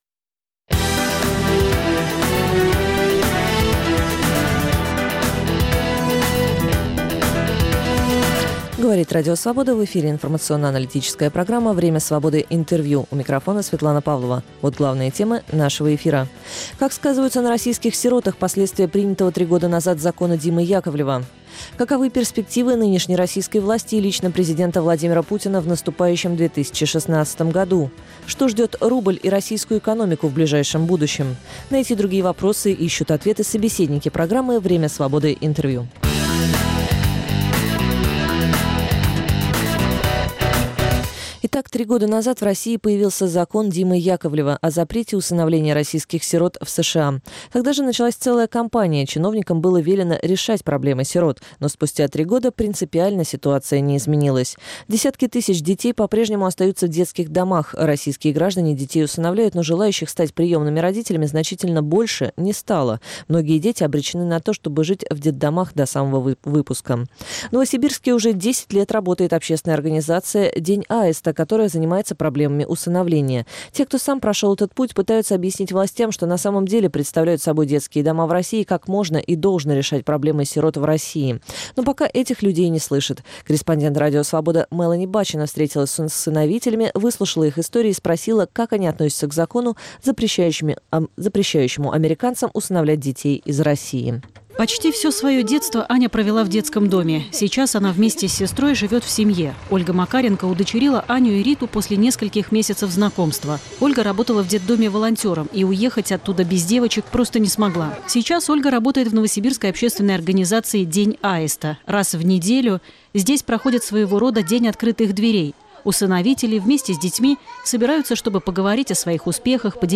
Время Свободы - Интервью